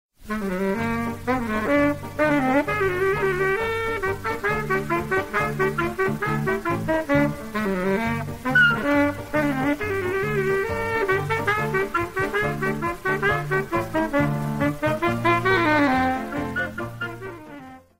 SAXOFÓN (viento madera)